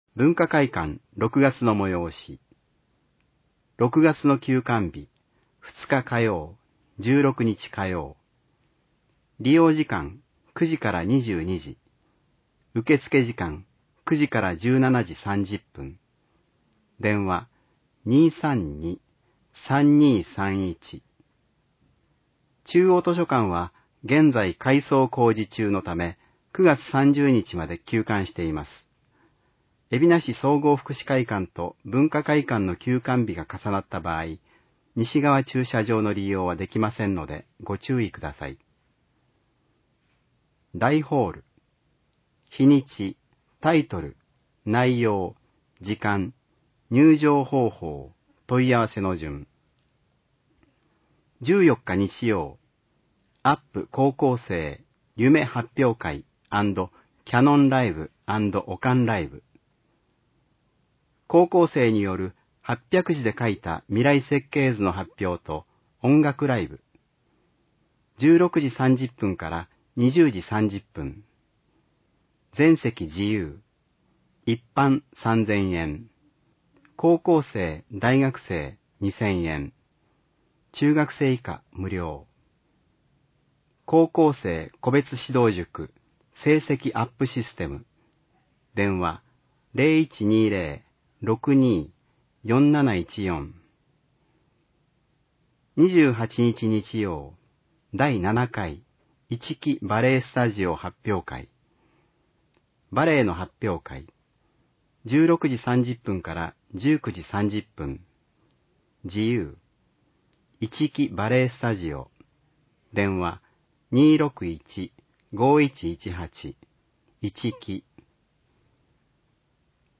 広報えびな 平成27年5月15日号（電子ブック） （外部リンク） PDF・音声版 ※音声版は、音声訳ボランティア「矢ぐるまの会」の協力により、同会が視覚障がい者の方のために作成したものを登載しています。